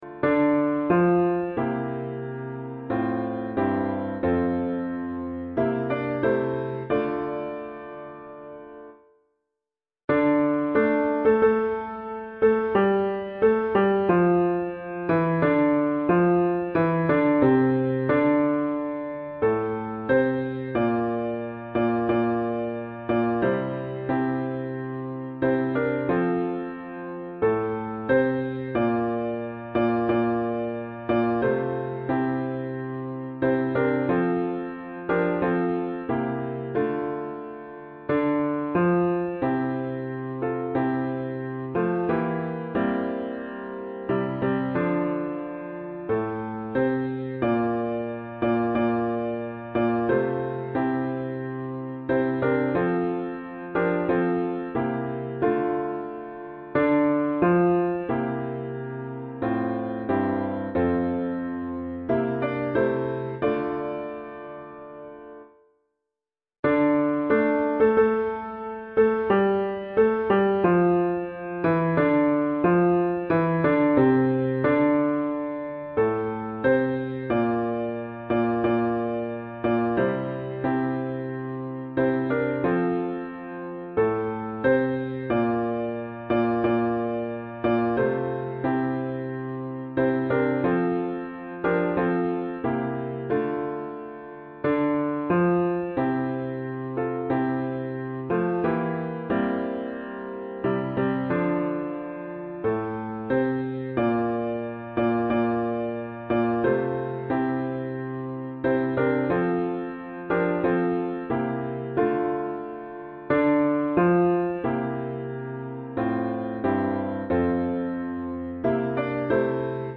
导读：本颂赞诗歌歌谱采用2017年修订版，录音示范暂用旧版，将逐渐更新。 独唱示范演唱者